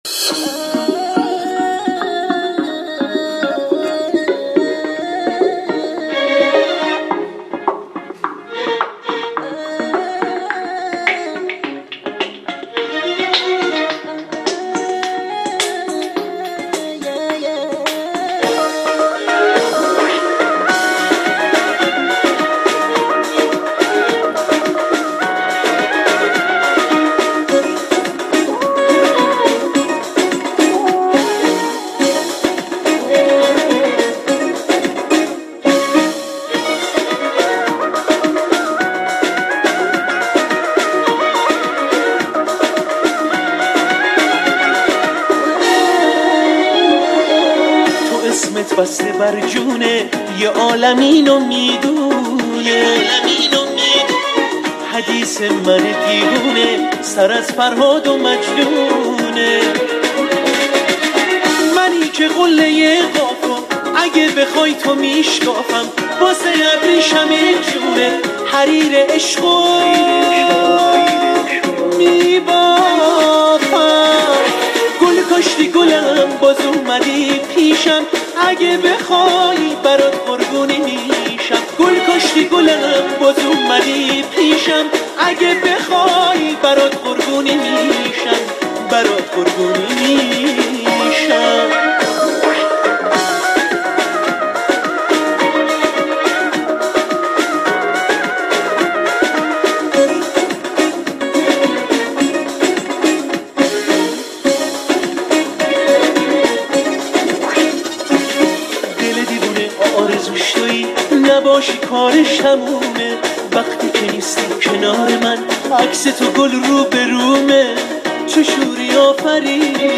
a Los Angeles Persian band